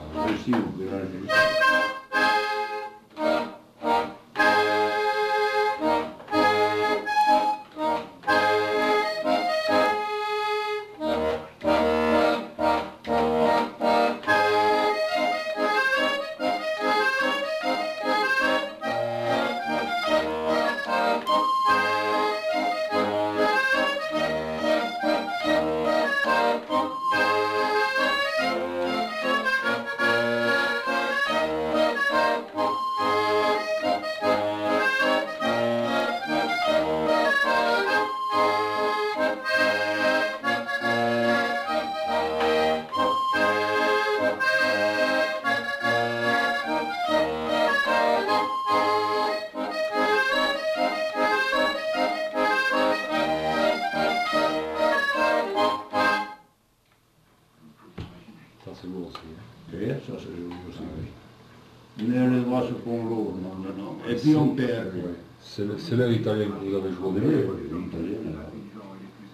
Aire culturelle : Bigorre
Lieu : Aulon
Genre : morceau instrumental
Instrument de musique : accordéon diatonique
Danse : mazurka